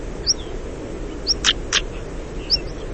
Saltimpalo
Saxicola torquata
Richiamo ‘uiit-trik-trik’. Il canto consiste di note doppie variabili, rapidamente ripetute; canta da posatoi esposti o durante i danzanti voli nuziali.
Saltimpalo_Saxicola_torquata.mp3